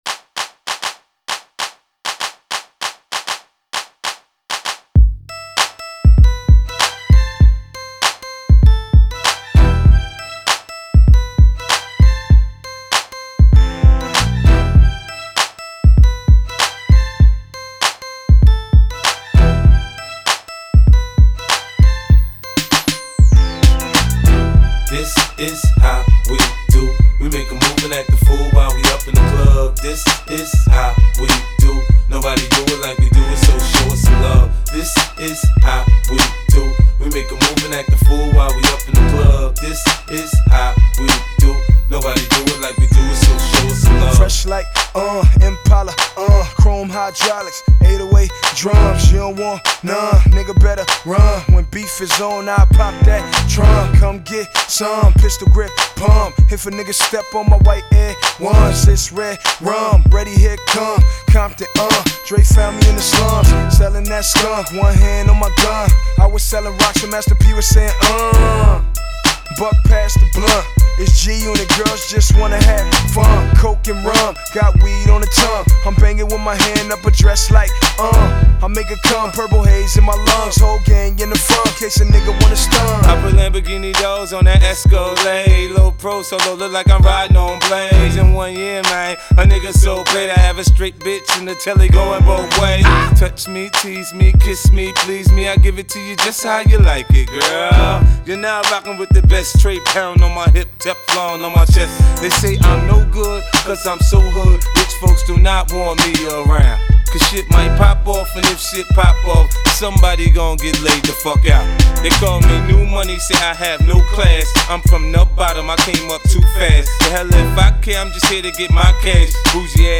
トラックがとにかくかっこいいの一言に尽きます。